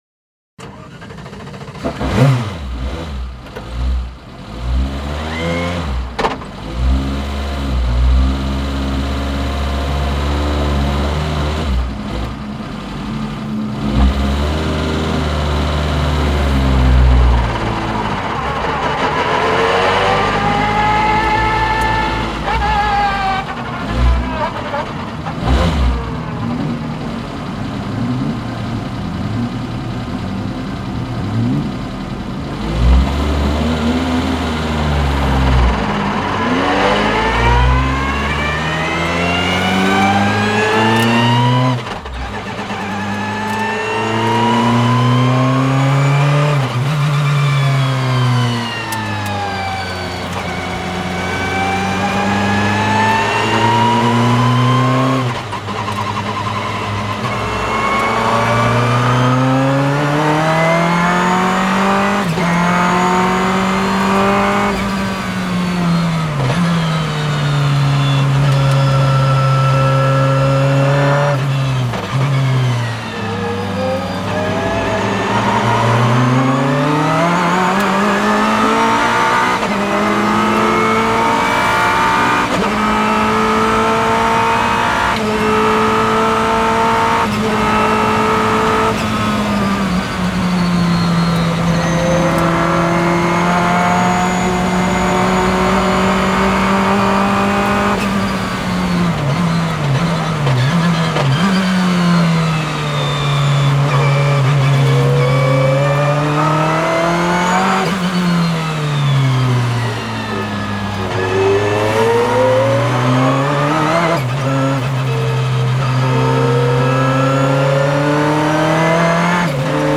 Ferrari F40 On Board At paul Ricard.mp3